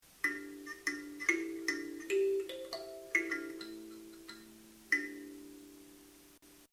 Kalimba with 9 bars